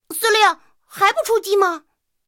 BT-7司令部语音3.OGG